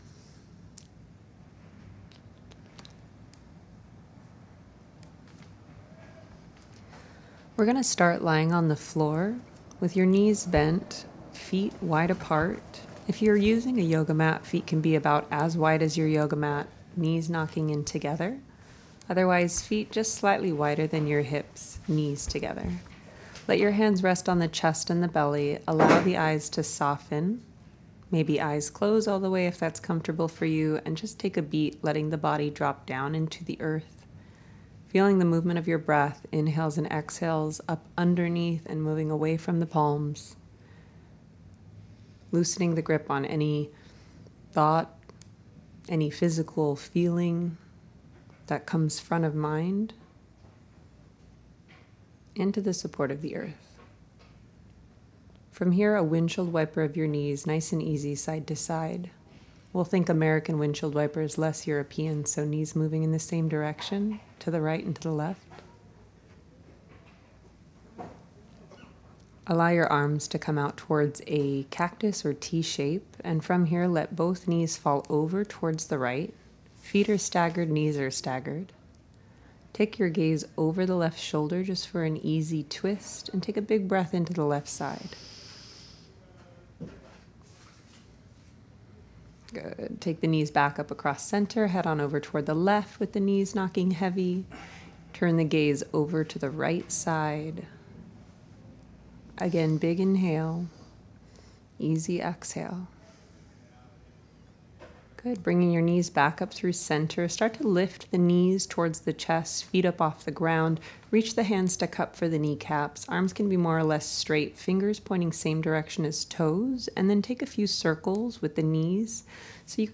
MISS OTHMAR is teaching over radio. MISS OTHMAR’s Meetings with Teachers is a series that collects the voices of different teachers and facilitators from a variety of fields and their approaches to leading the listener through exercises over radio. https